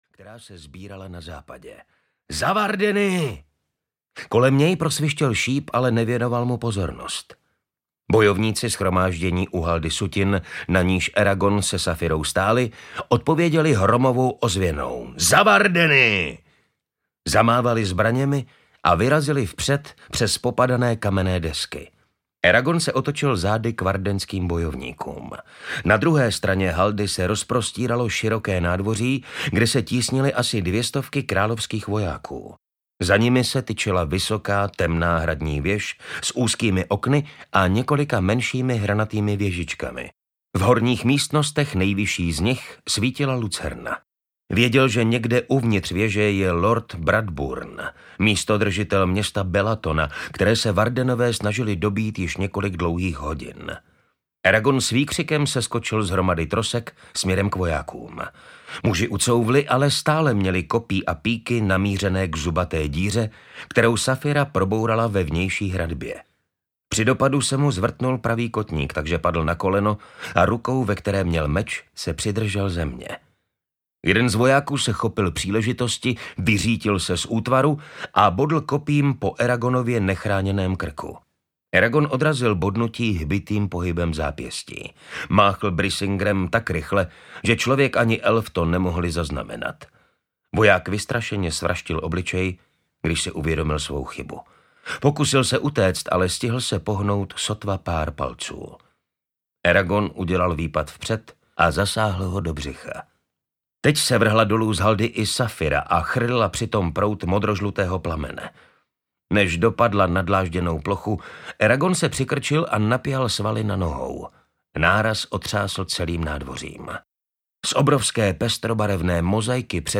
Inheritance audiokniha
Ukázka z knihy
• InterpretMartin Stránský